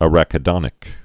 (ărə-kĭ-dŏnĭk)